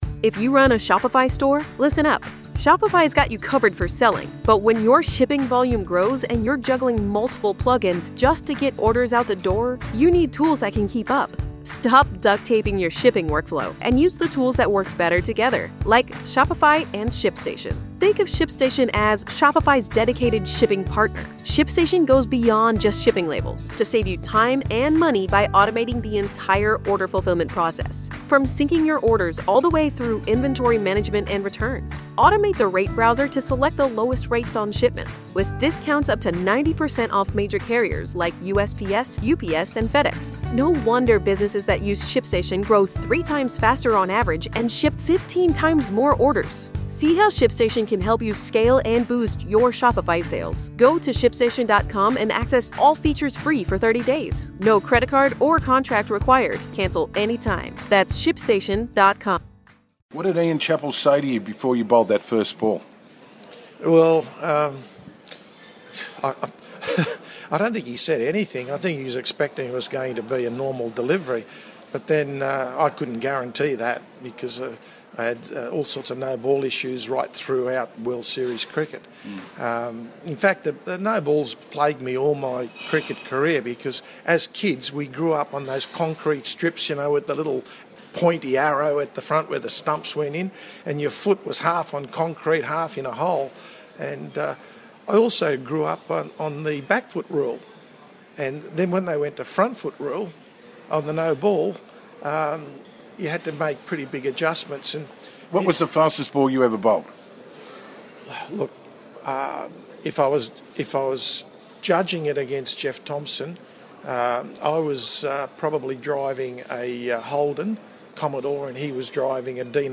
Greg Matthews Interviews Len Pascoe Part 2